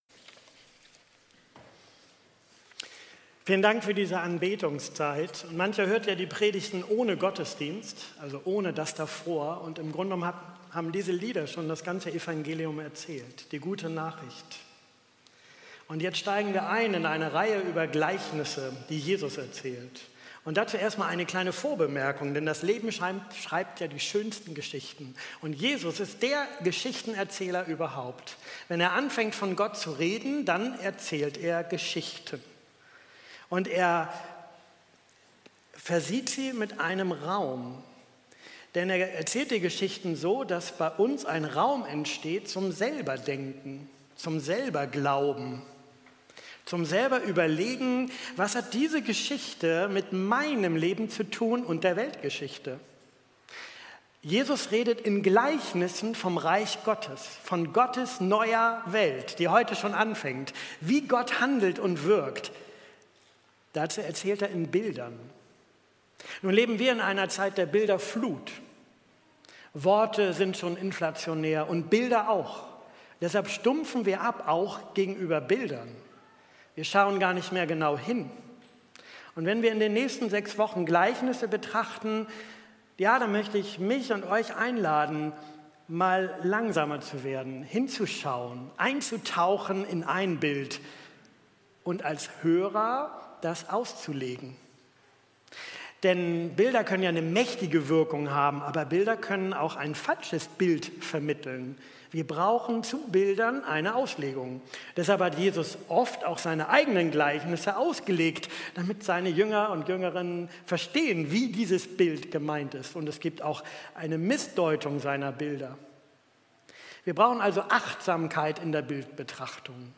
Gottesdienst
Predigttext: Lukas 14, 12-24